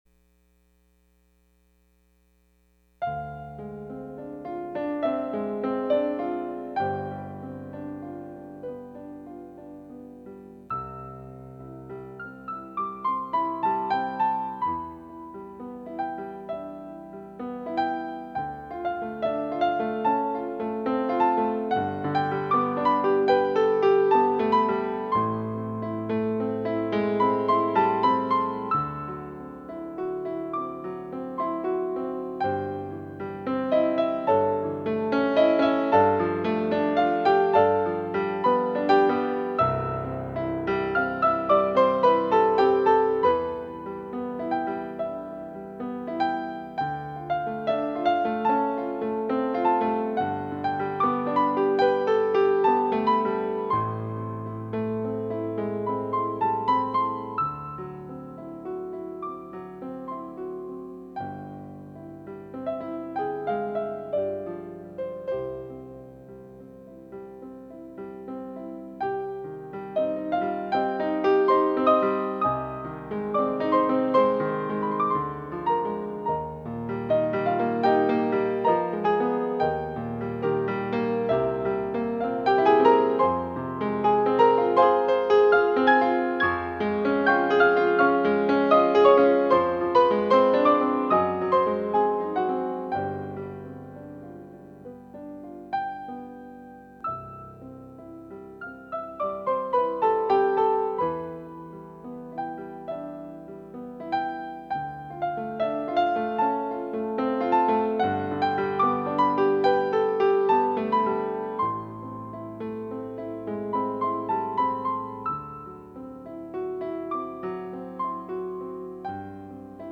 Music for Adagio